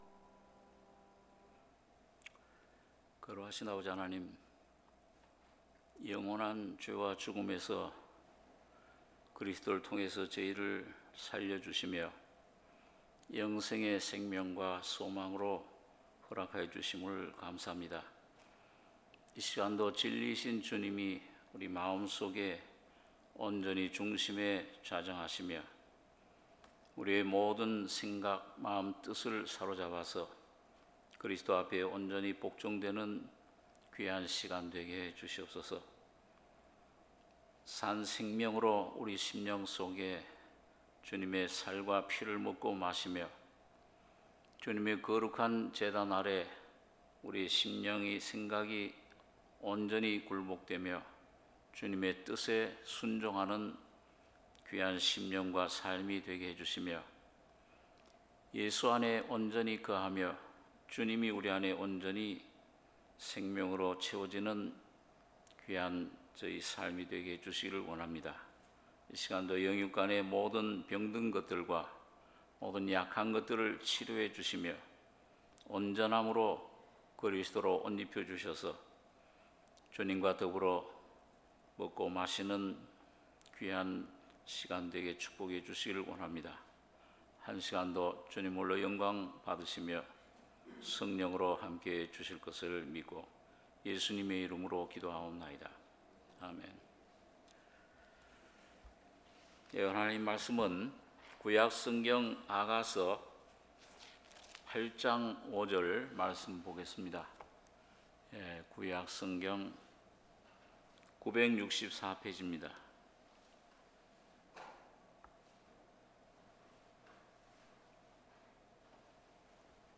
수요예배